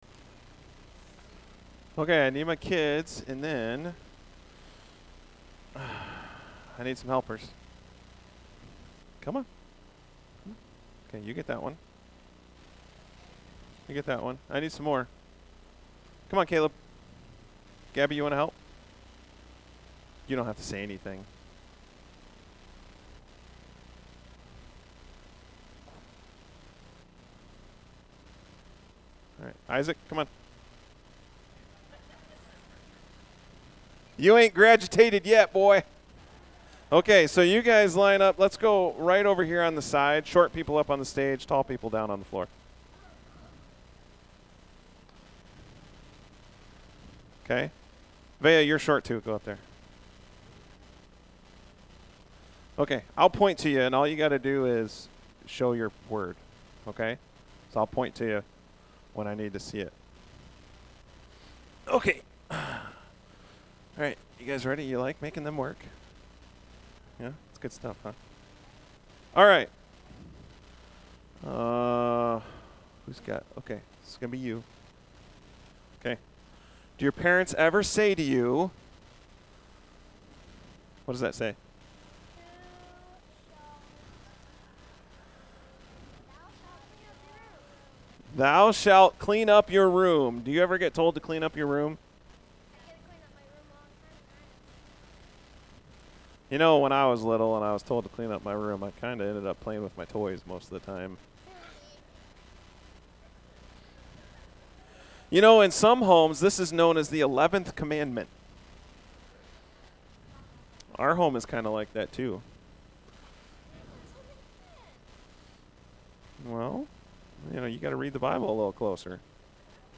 A message from the series "Easter Season."